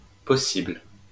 l laisser,
possible, seul, ville law